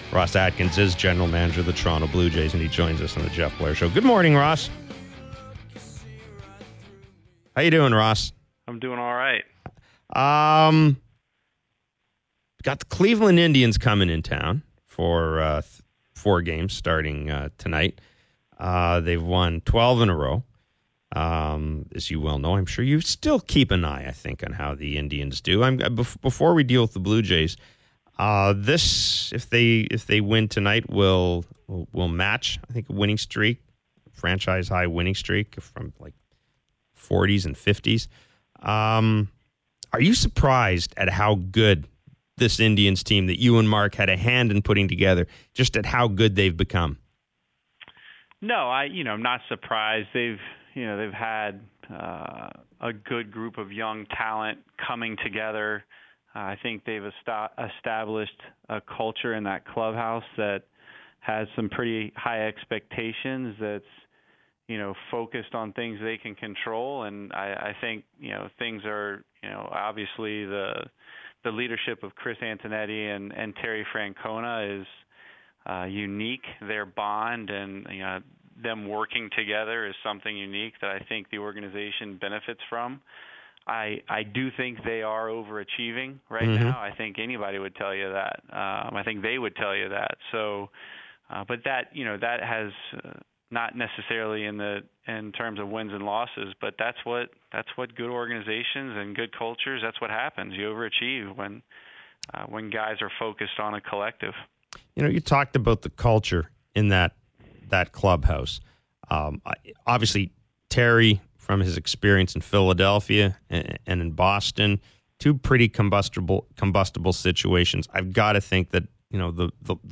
Sportsnet 590 The Fan: Interview with Toronto Blue Jays GM Ross Atkins